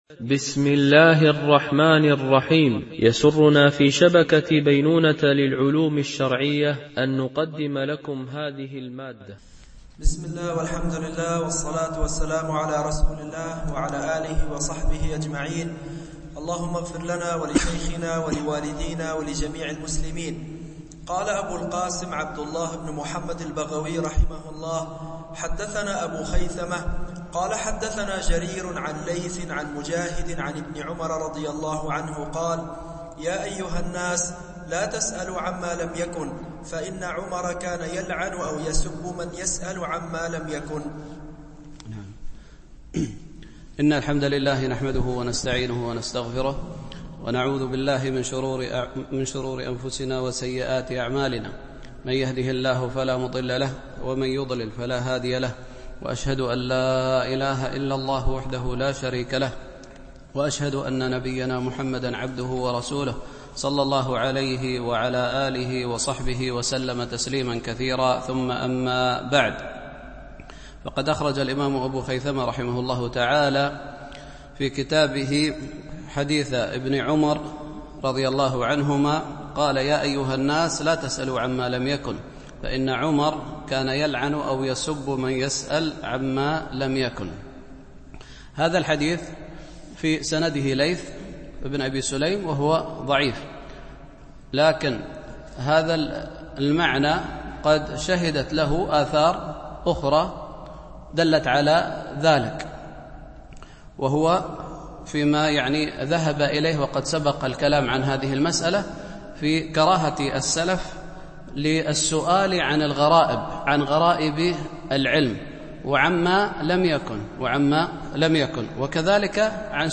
شرح كتاب العلم لأبي خيثمة ـ الدرس41 (الأثر 144-154)